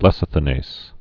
(lĕsə-thə-nās, -nāz)